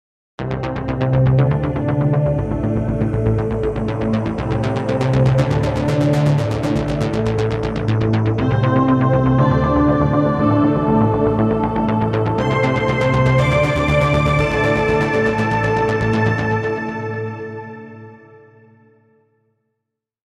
Dramatic Tension & Mystery Synth Arpeggiator Music
Genres: Sound Logo